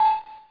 beep.mp3